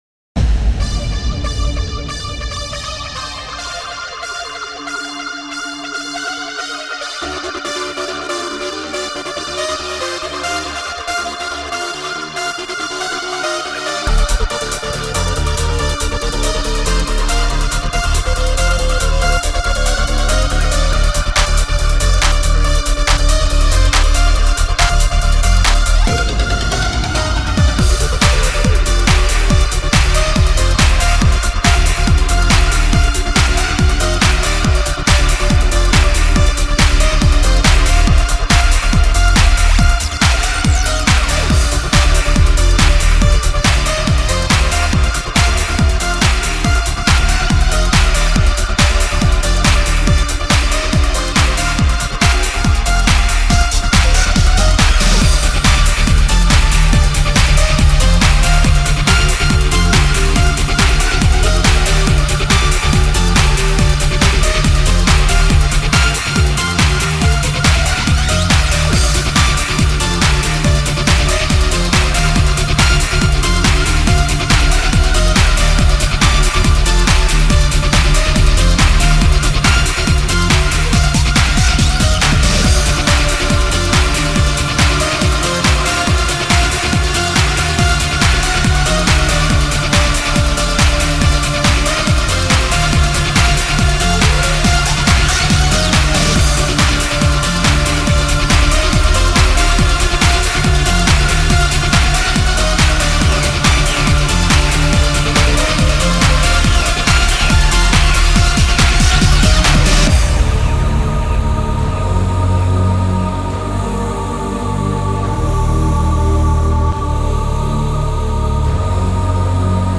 Категория: Club Music - Клубная музыка